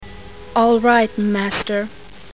Category: Radio   Right: Personal
Tags: Radio The Curse Of Dracula Play Horror Bram Stoker